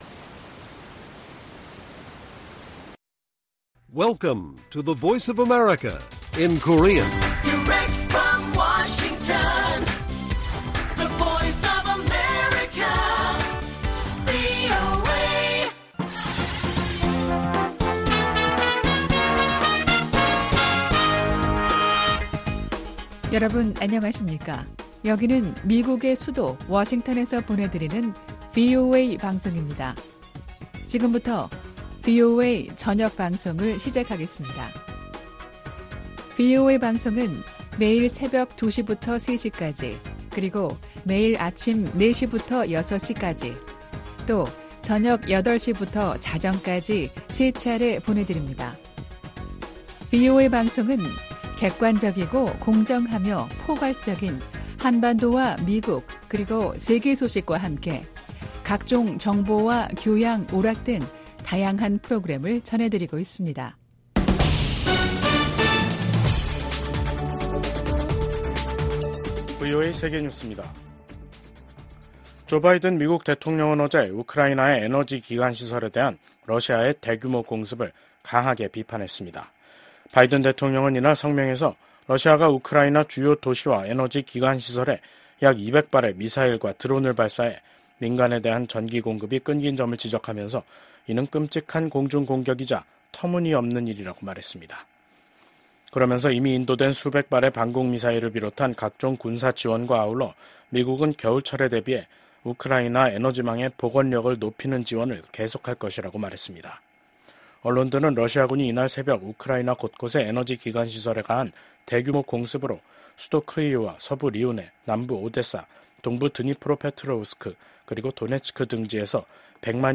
VOA 한국어 간판 뉴스 프로그램 '뉴스 투데이', 2024년 11월 29일 1부 방송입니다. 우크라이나와의 전쟁이 치열해지는 가운데 러시아의 안드레이 벨로우소프 국방장관이 북한을 공식 방문했습니다. 미국 국무부가 북한 국적자를 포함한 개인 3명과 러시아, 중국 회사에 제재를 단행했습니다. 북한과 중국이 강제 북송된 탈북민을 강제 노동에 동원해 경제적 이익을 공유하고 있다는 연구 결과가 나왔습니다.